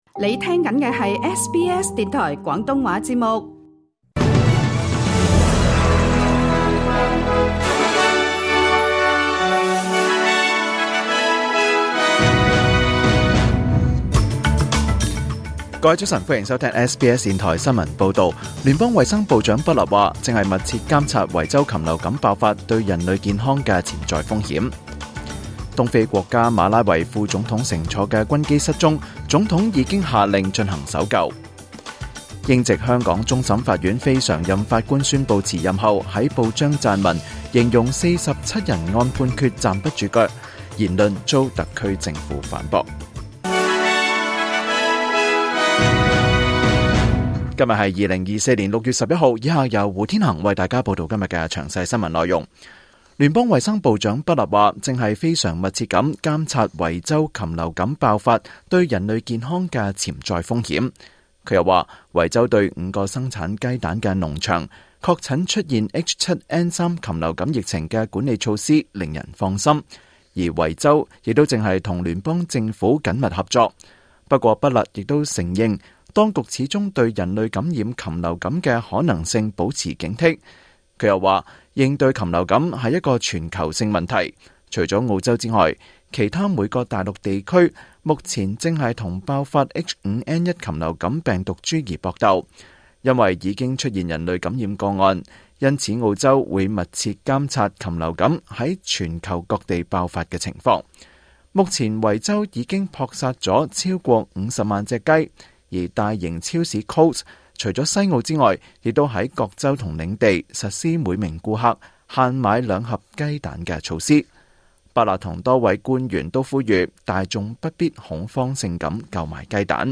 2024年6月11日SBS廣東話節目詳盡早晨新聞報道。